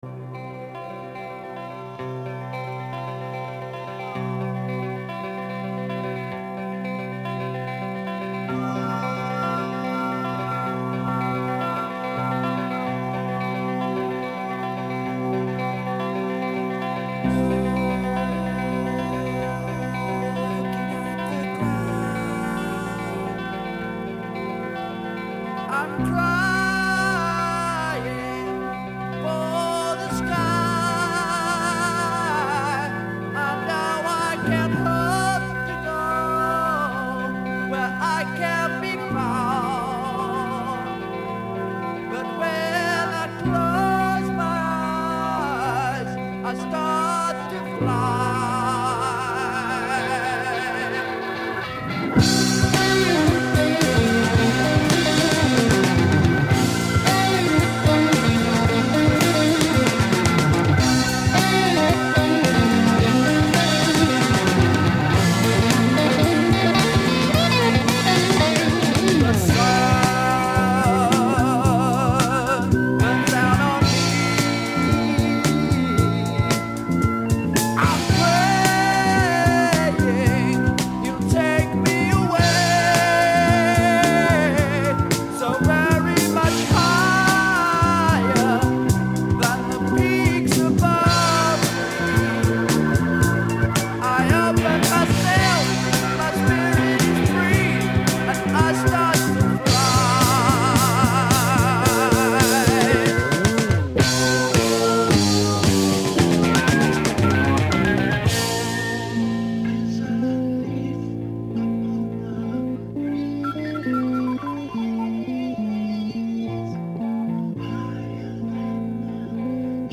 singer, song-writer and guitar player.